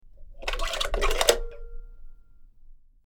Rotary Phone 04
Rotary_phone_04.mp3